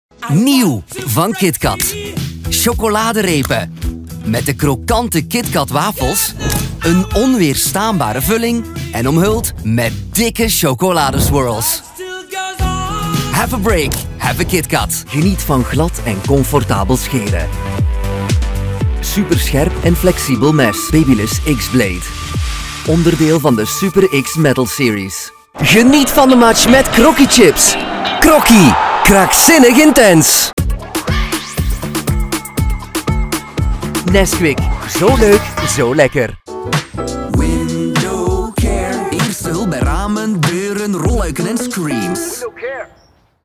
Commercieel, Jong, Natuurlijk, Stedelijk, Stoer
Commercieel